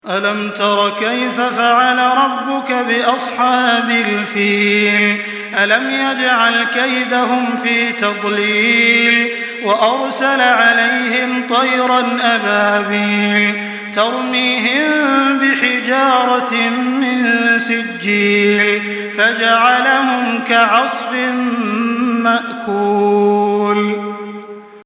Surah Fil MP3 by Makkah Taraweeh 1424 in Hafs An Asim narration.
Murattal